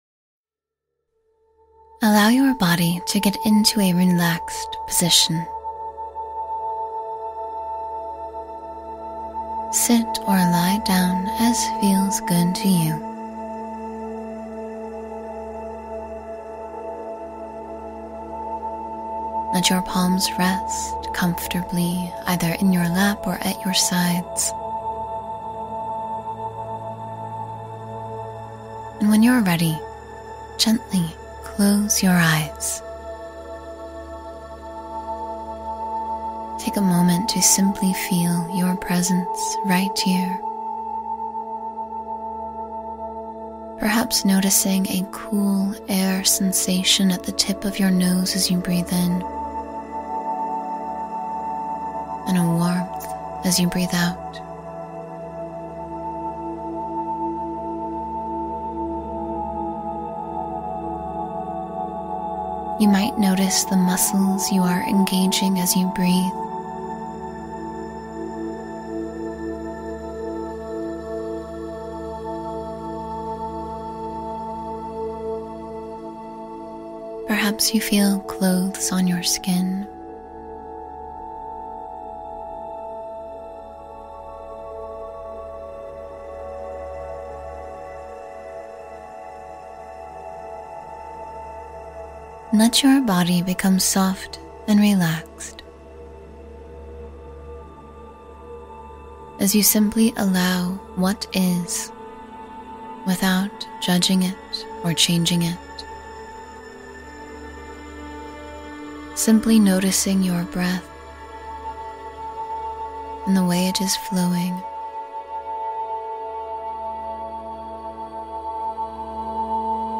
Guided Meditation for Everyone — 10 Minutes of Instant Calm